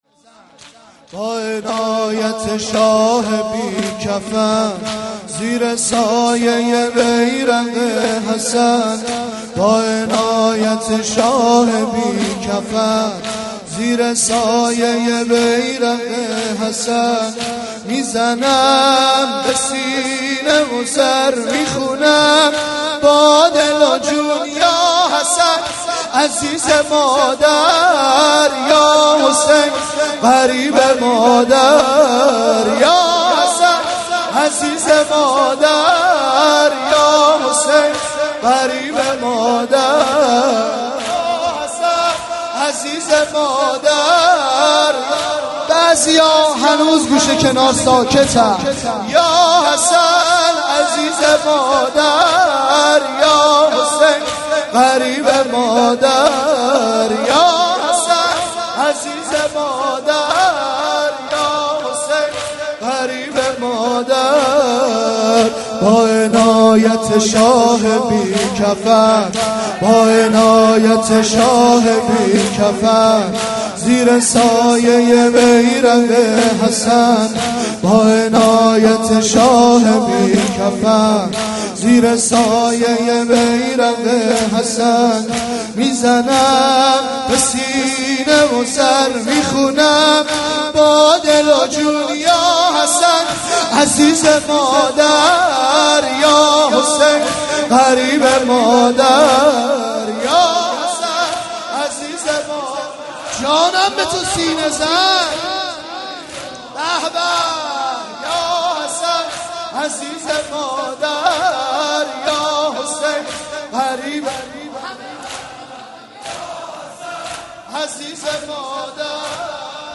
03.sineh zani.mp3